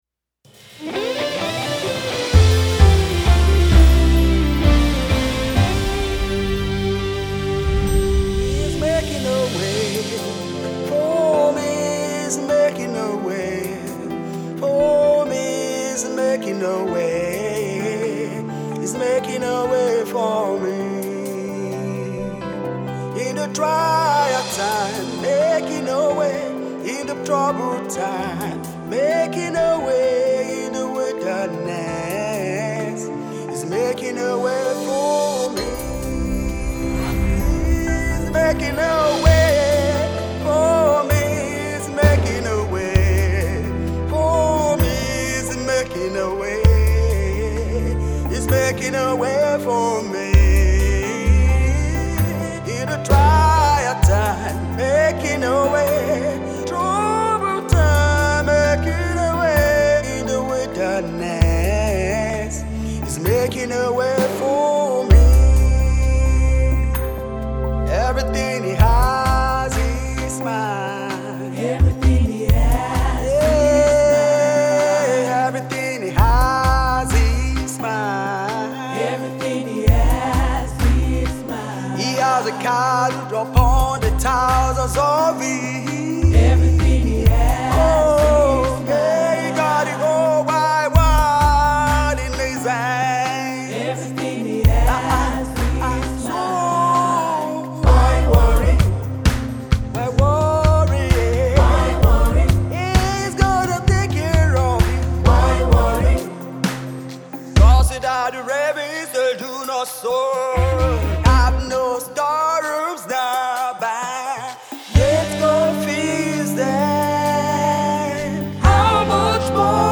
Gospel Single
soul-stirring